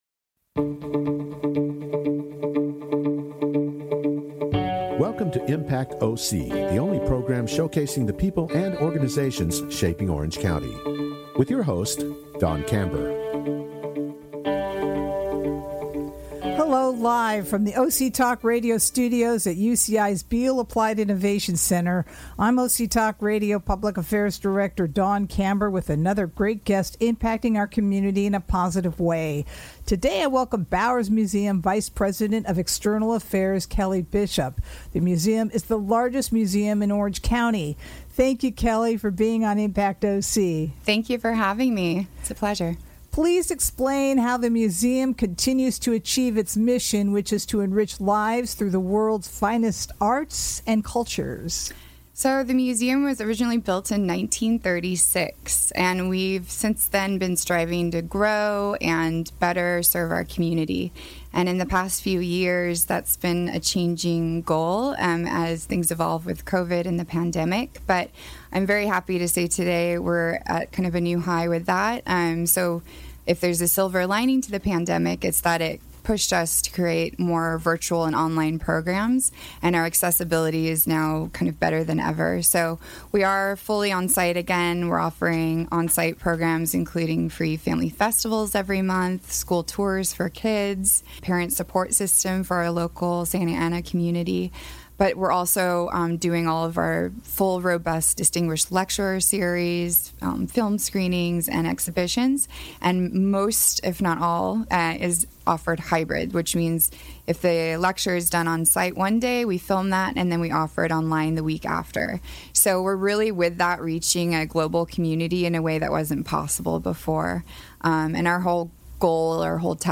Hear about the museum’s current exhibitions and how the museum connects with the community onsite and online. Only on OC Talk Radio, Orange County’s Only Community Radio Station which streams live from the University of California-Irvine’s BEALL APPLIED INNOVATION CENTER.